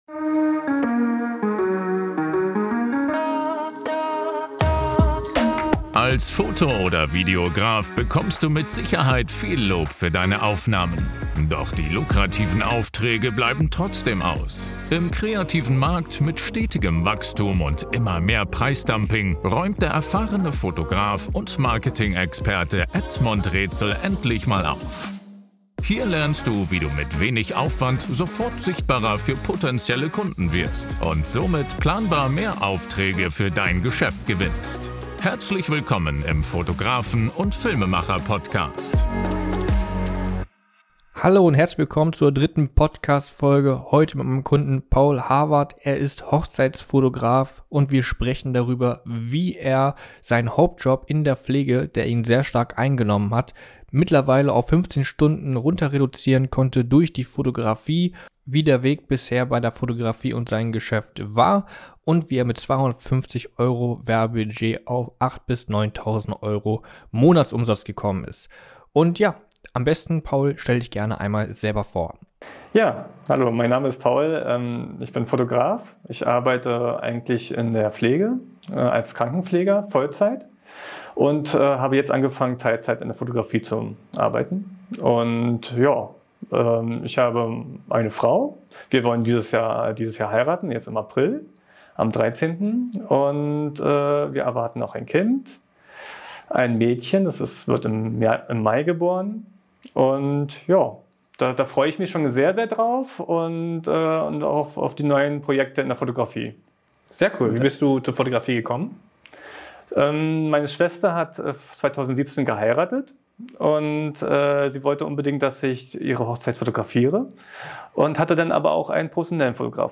Vom stressigen Hauptberuf zur Fotografie - Interview